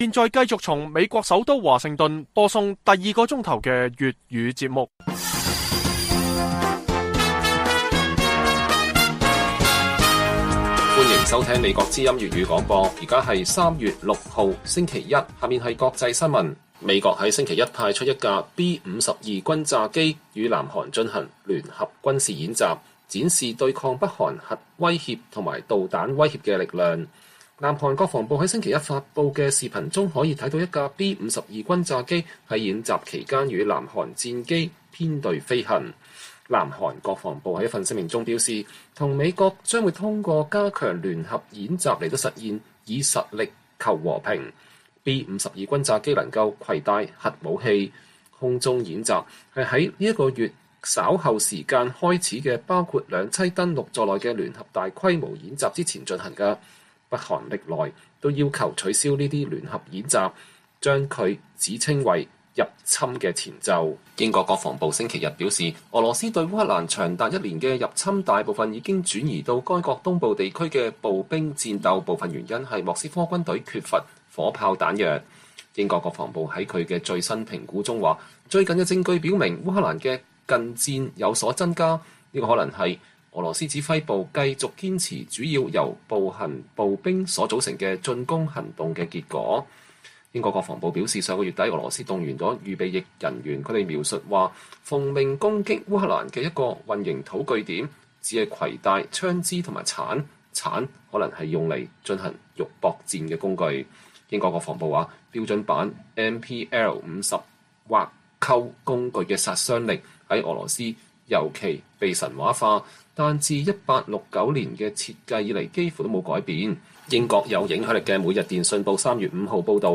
粵語新聞 晚上10-11點: 美國派出B-52轟炸機參加美韓聯合演習